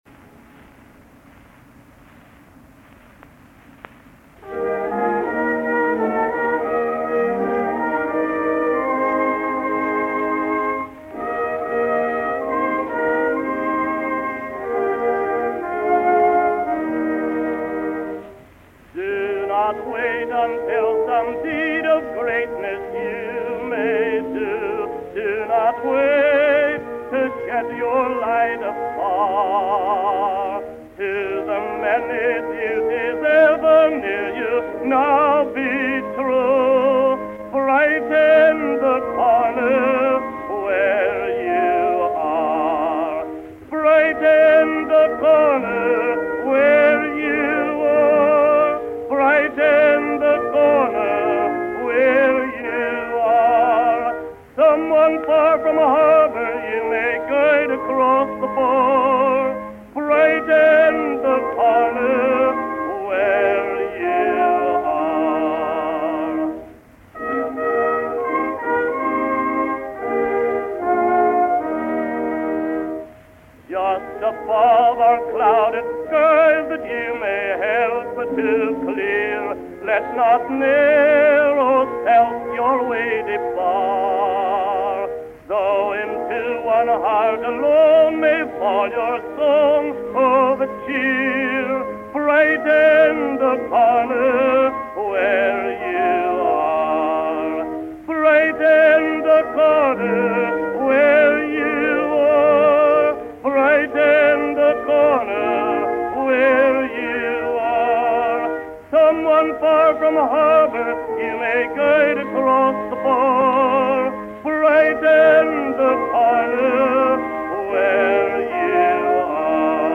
sings his signature hymn